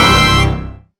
victory.wav